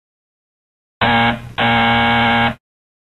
buzzer-bruitage.mp3
KBivqVMxwoO_buzzer-bruitage.mp3